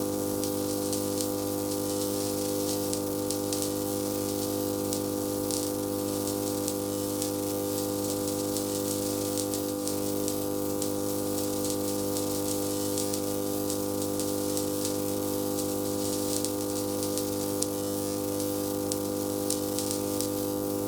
ElectricBuzzLoop.ogg